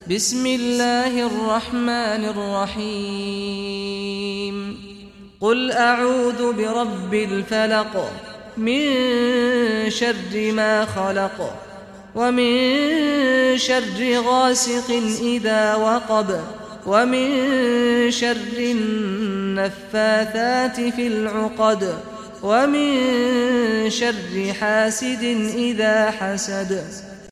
Surah Al-Falaq Recitation by Sheikh Saad Ghamdi
Surah Al-Falaq, listen online mp3 tilawat / recitation in Arabic in the beautiful voice of Sheikh Saad al Ghamdi.